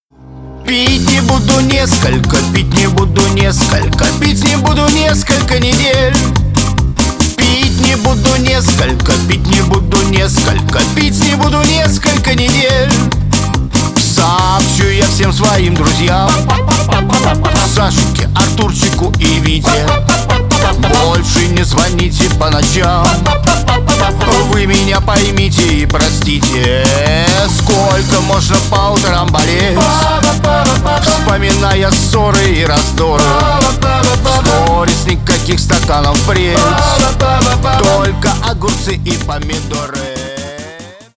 Рингтоны на звонок
Нарезка припева на вызов